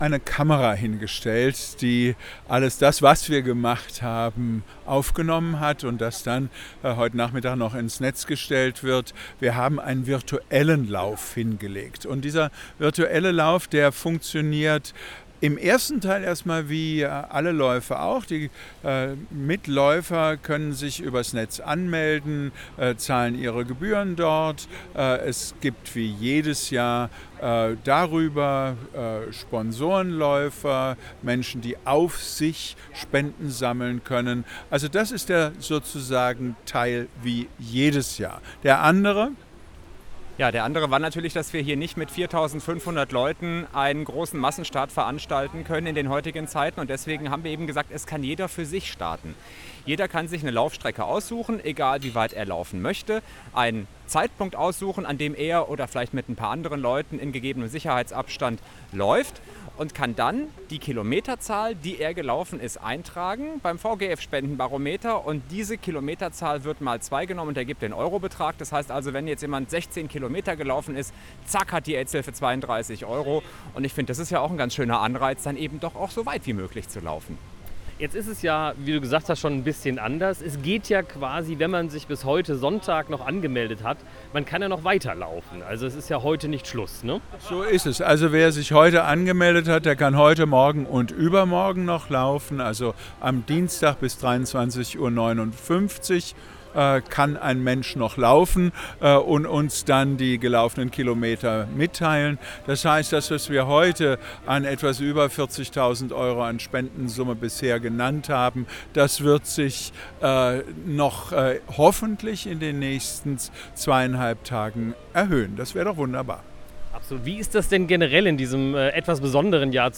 1207_laufinterview.mp3